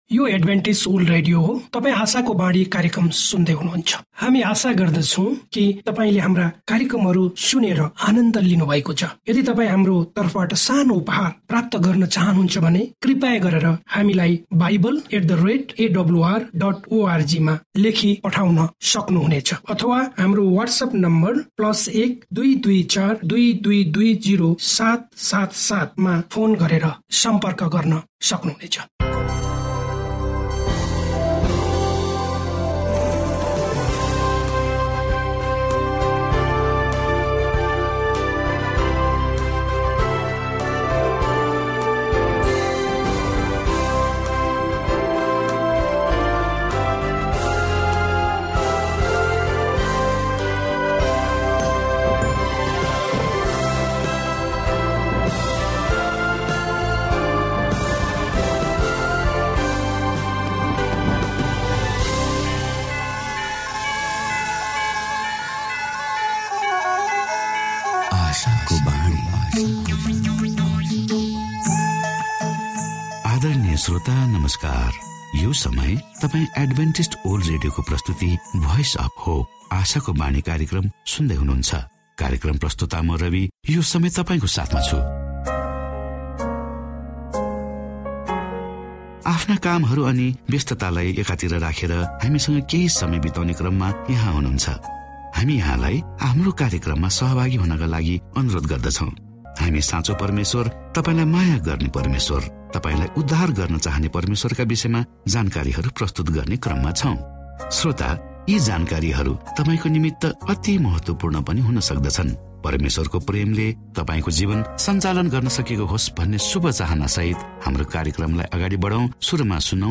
अनउत्तरीत प्रश्न. म को हुं , भजन - सुन म केहि भन्छु.